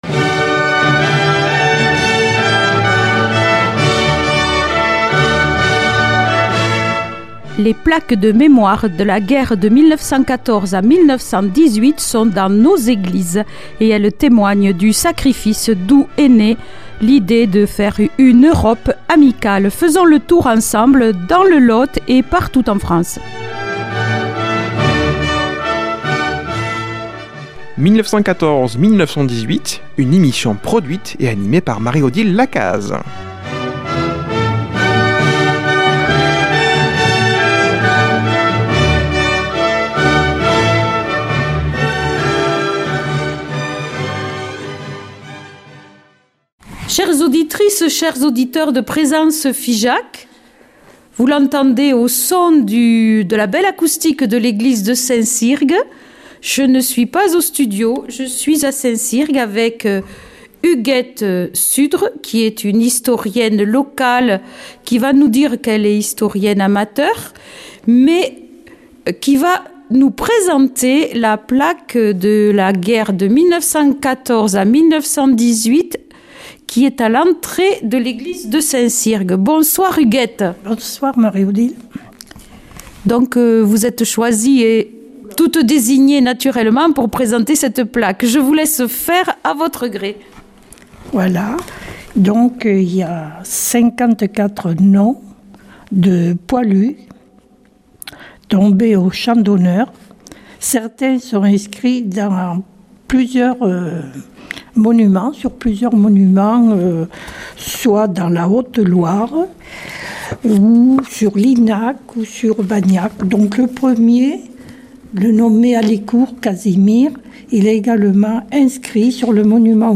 qui se trouve aujourd'hui à l'église de St Cirgues